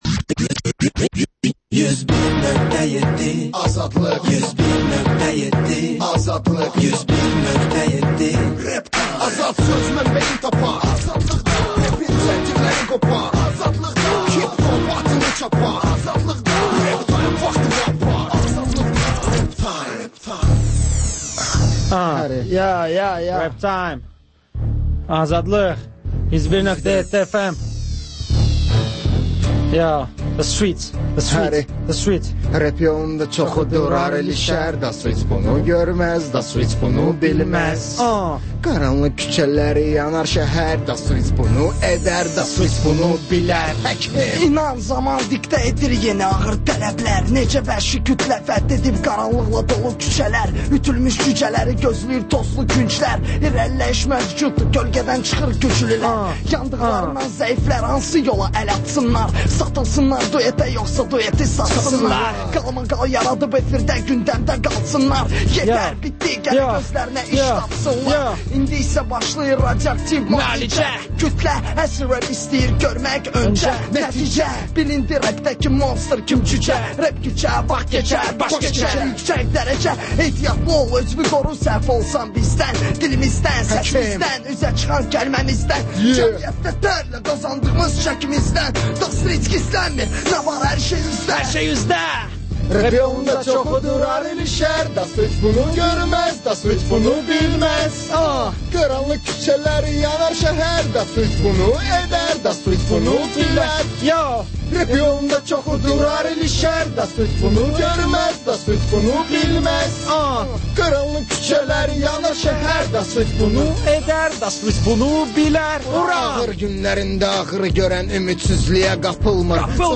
Xəbərlər, REP-TIME: Gənclərin musiqi verilişi